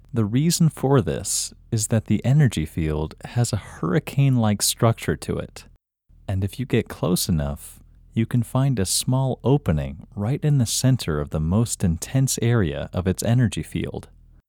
IN – Second Way – English Male 6